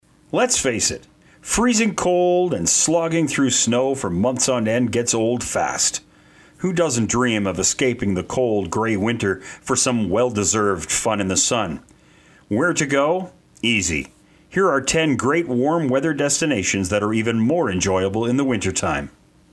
Mature Canadian Male Voice. Warm and engaging,or punchy and hard sell, adaptable for all situations.